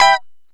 Lng Gtr Chik Min 12-E3.wav